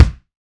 West Metro Kick.wav